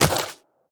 biter-roar-mid-8.ogg